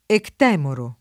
[ ekt $ moro ]